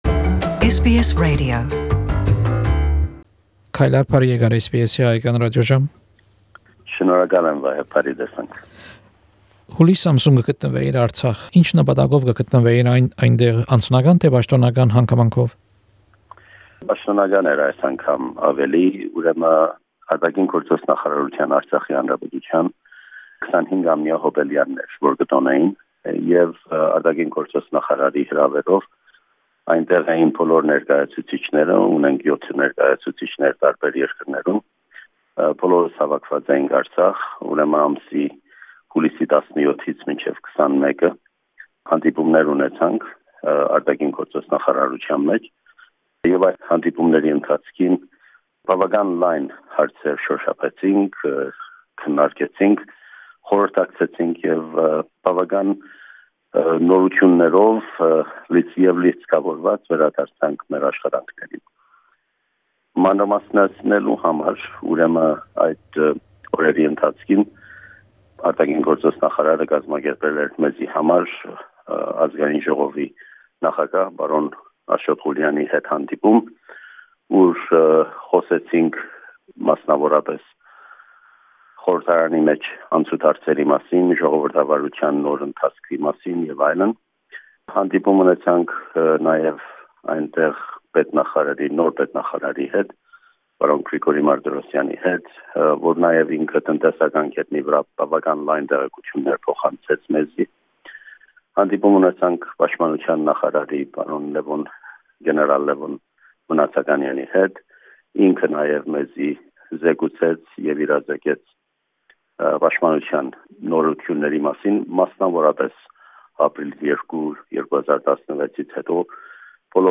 An interview with Mr Kaylar Michaelian, the Permanent Representative of Artsakh Republic to Australia, about his recent official trip to Artsakh and ARI project.